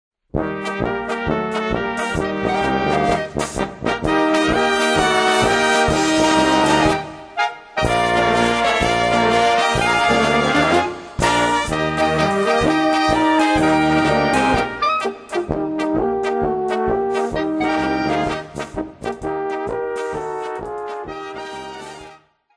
polka
valčík
mambo